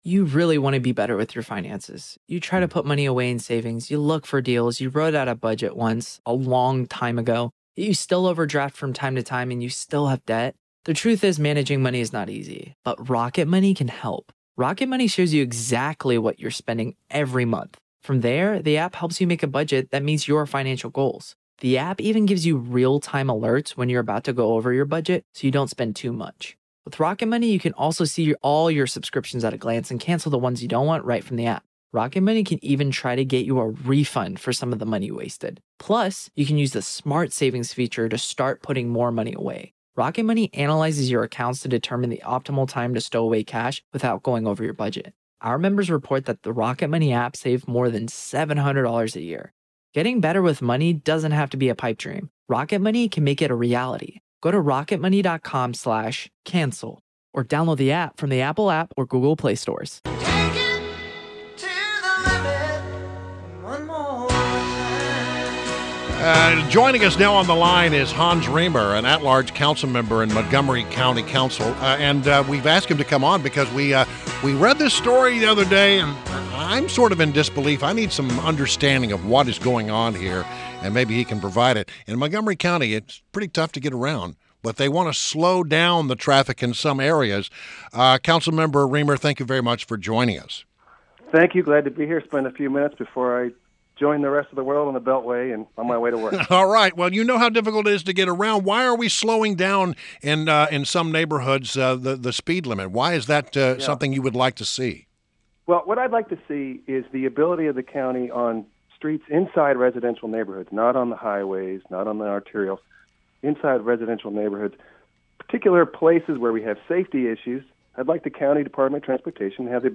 INTERVIEW — HANS RIEMER — (pronounced REEM-er) — At-large Councilmember at Montgomery County Council